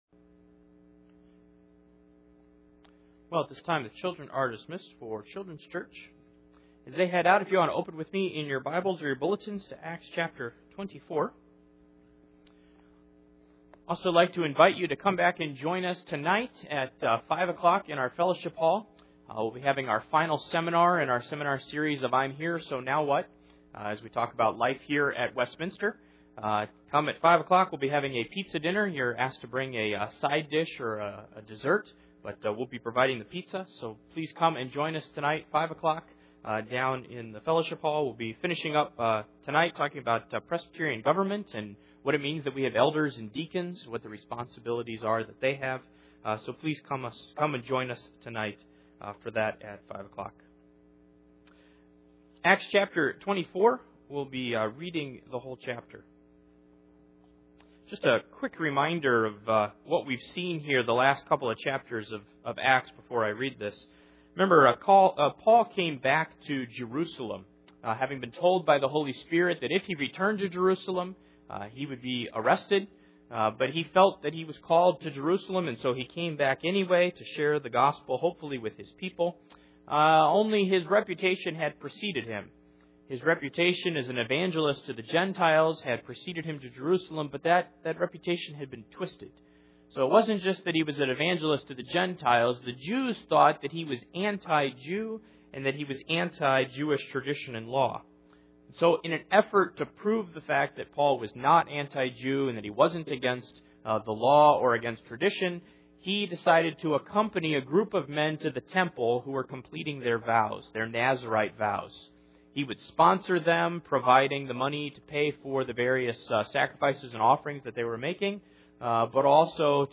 The Book of Acts Passage: Acts 24:1-27 Service Type: Sunday Morning %todo_render% « Past